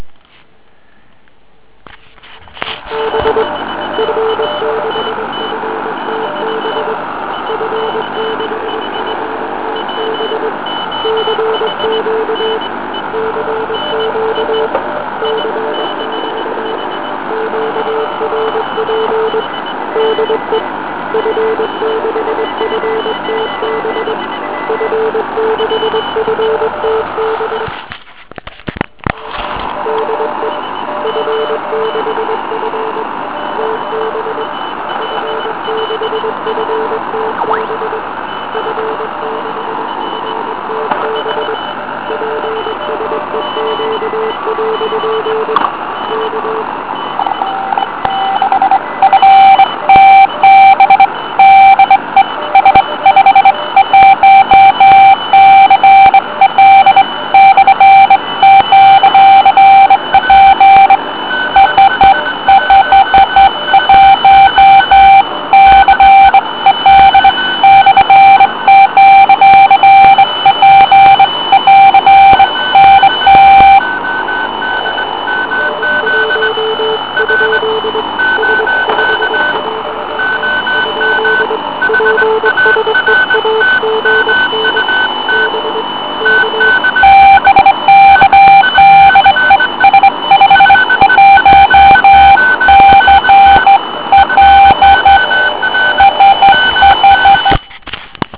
Nahrávka je samozřejmě dělána na Rozkmita.